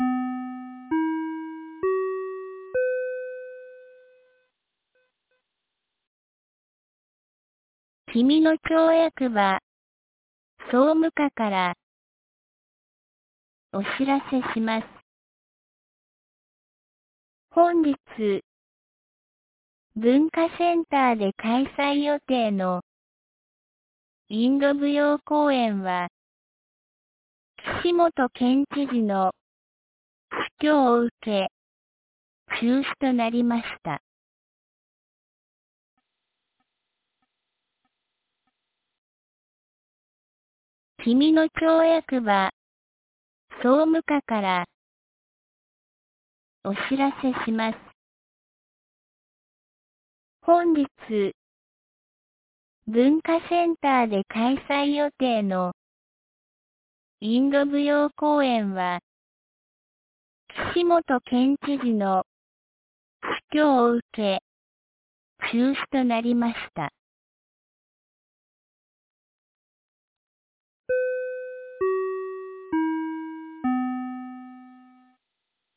2025年04月16日 12時06分に、紀美野町より全地区へ放送がありました。